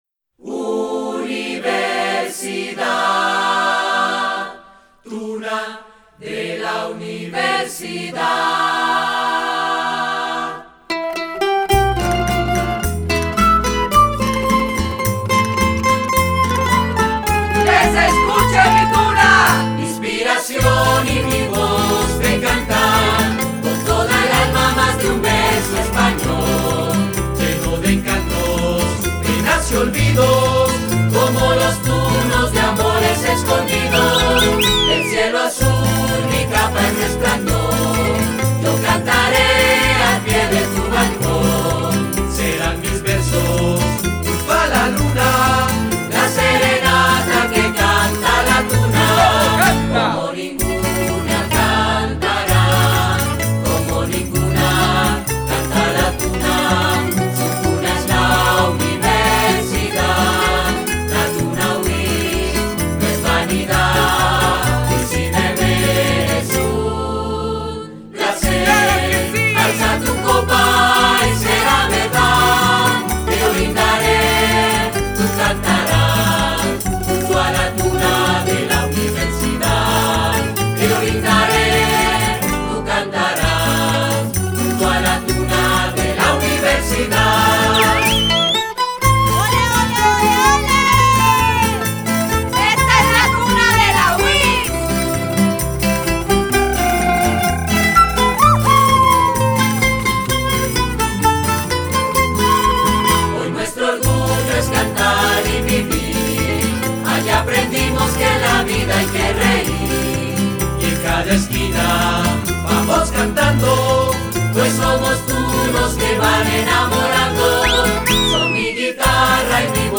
Música Andina Colombiana